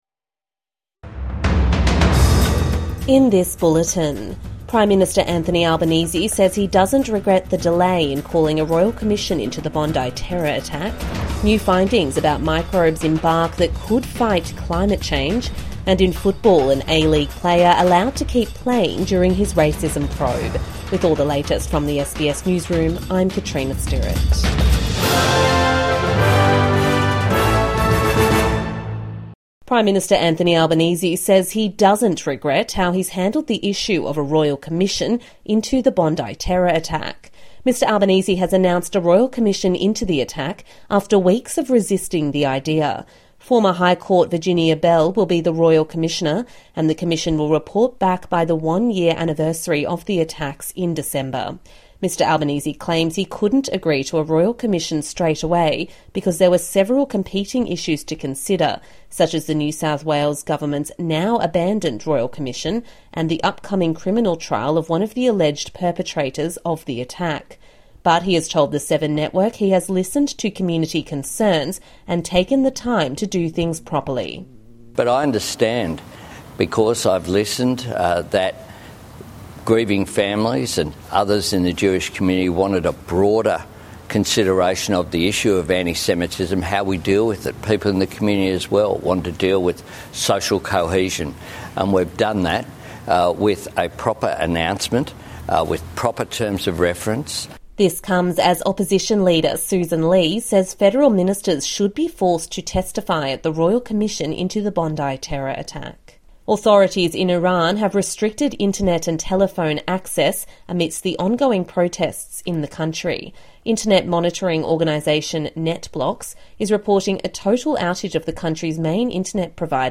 A bulletin of the day’s top stories from SBS News.